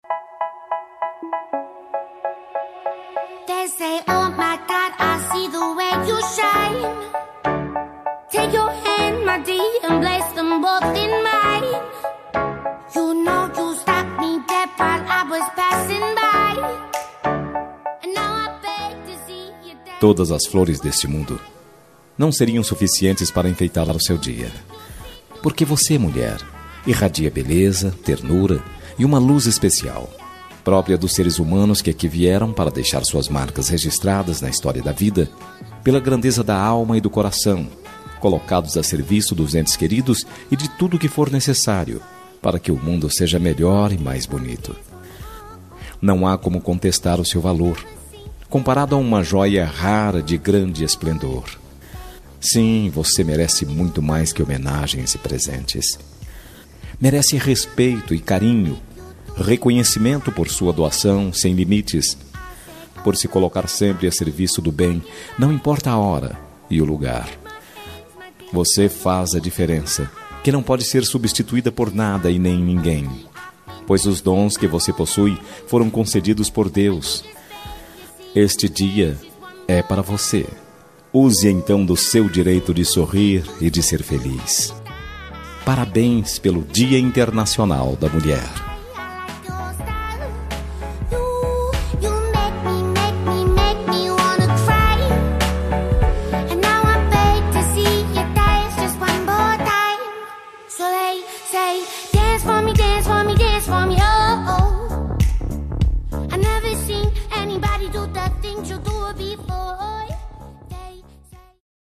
Dia das Mulheres Neutra – Voz Masculina – Cód: 5279